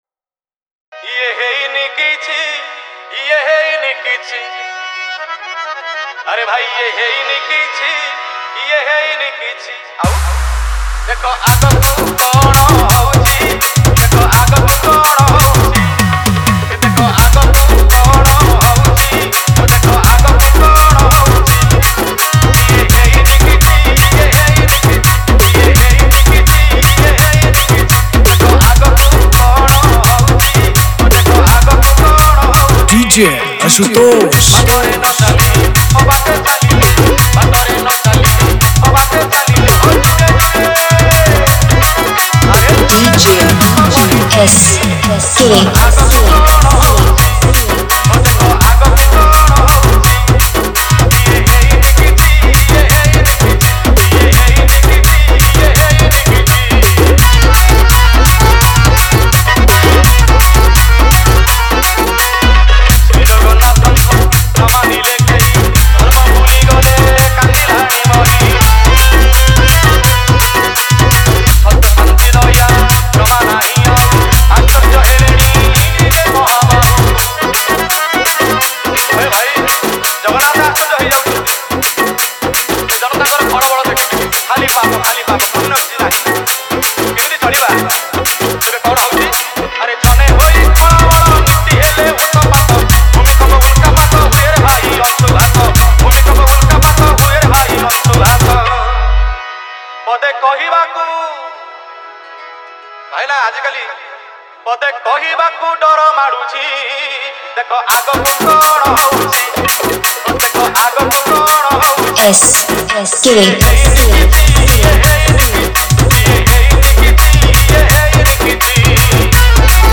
Bhajan Dj Song Collection 2021